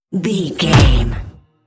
Trailer dramatic hit
Sound Effects
Atonal
heavy
intense
dark
aggressive